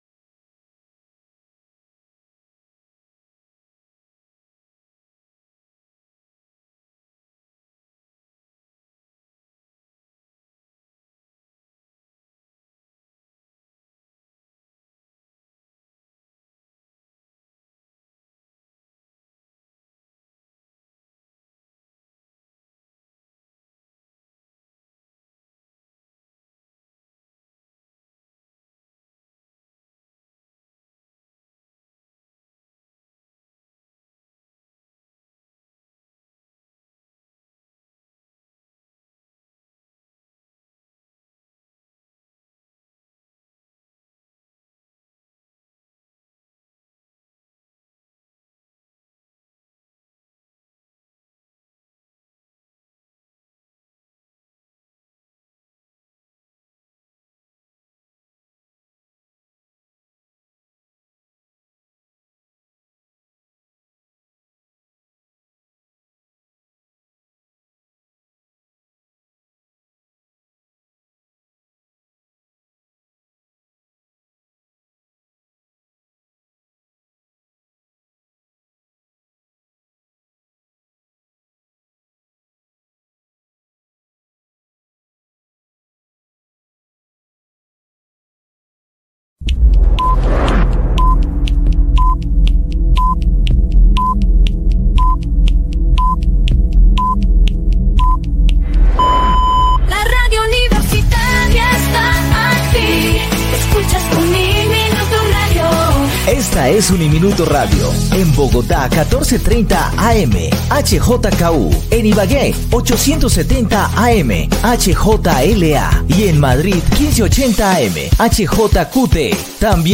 Él Árbol Rojo: conversación con la escritora